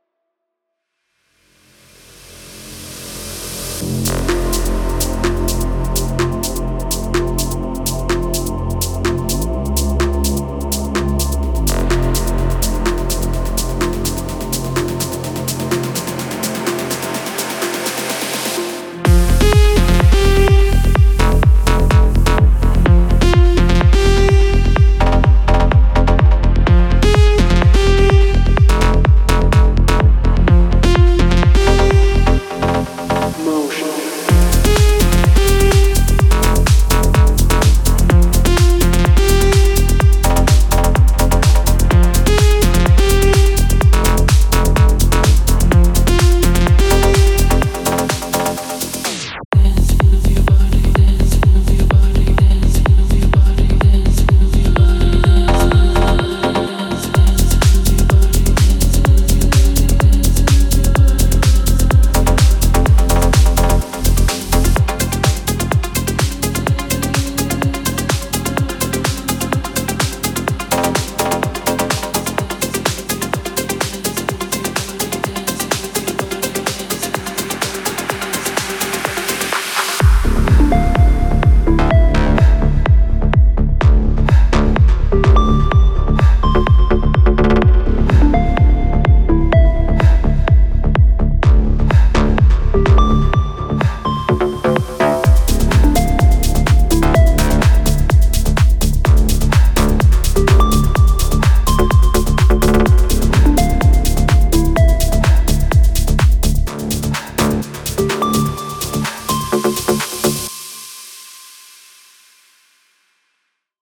Genre:Techno
最先端のメロディックテクノを制作するために必要なすべてが揃っています。
デモサウンドはコチラ↓